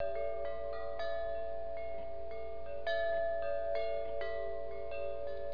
MOUNTAIN Large Copper Wind Chime with Blue Patina
Copper and Cedar
5 tubes (1½" Dia.)
The most unique feature of this large size wind chime is its sound. The 5 copper tubes with a solid wood ball clapper give this wind chime soft, mellow tones that are very soothing to listen to. Keep in mind that with their soft tones they will not have the same volume as some other wind chimes.